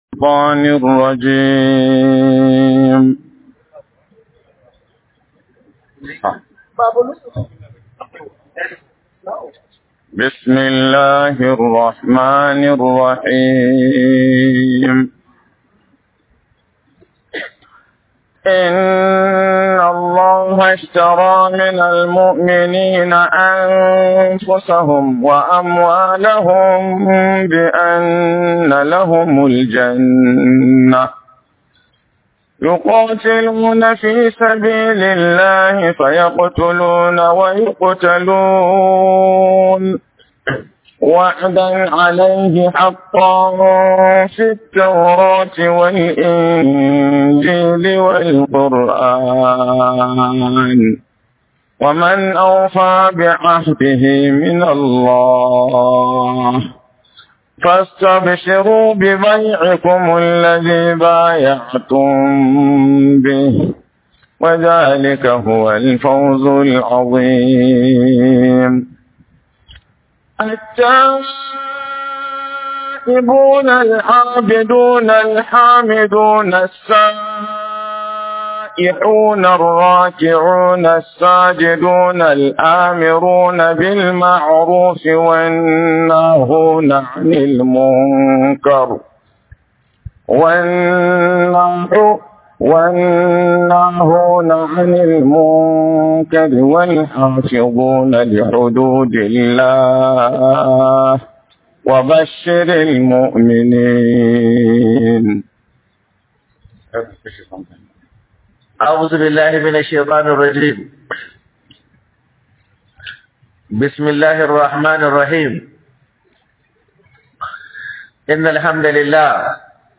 Ramadan Tafsir